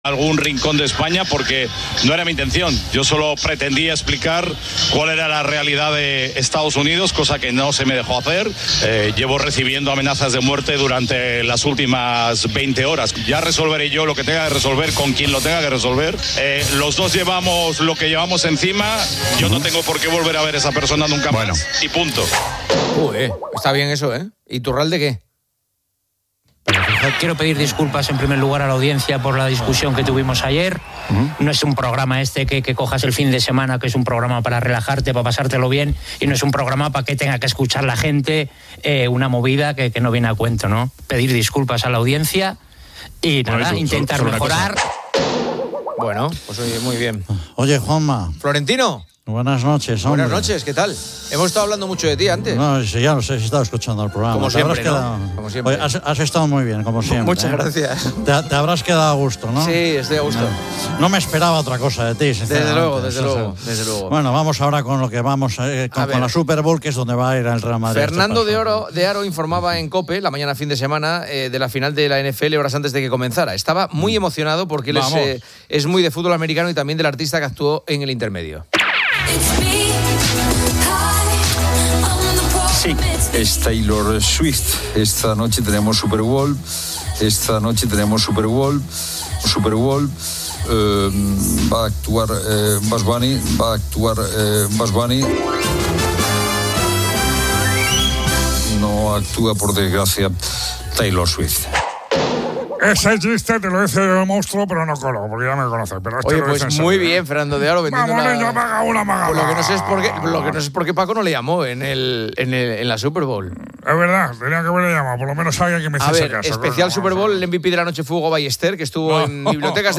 Poniendo las Calles 01:30H | 12 FEB 2026 | Poniendo las Calles El programa inicia con disculpas y busca un tono relajado.